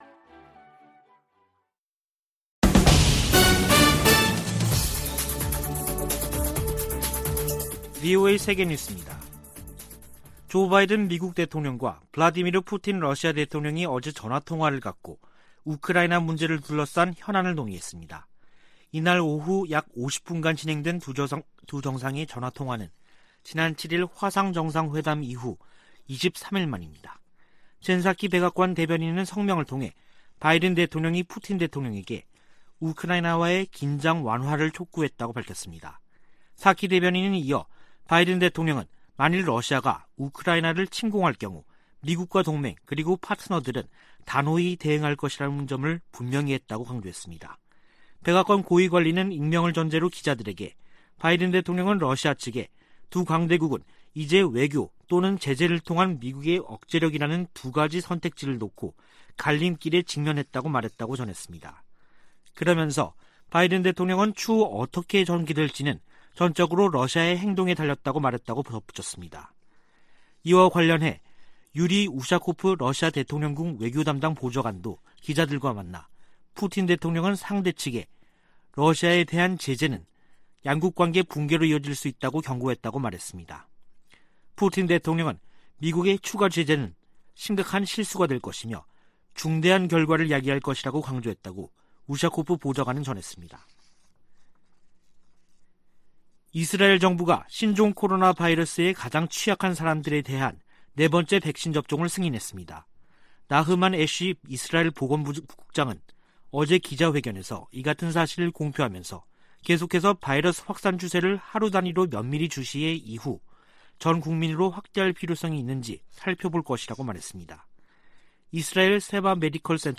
VOA 한국어 간판 뉴스 프로그램 '뉴스 투데이', 2021년 12월 31일 2부 방송입니다. 미국과 한국이 종전선언 문안에 합의한 것으로 알려지면서 북한과의 협의로 진전될지 주목됩니다. 2021년 미국은 7년 만에 가장 적은 독자 대북 제재를 부과했습니다. 북한의 곡물생산량이 지난해 보다 증가했지만 식량난은 여전한 것으로 분석됐습니다.